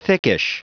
Prononciation du mot thickish en anglais (fichier audio)
Prononciation du mot : thickish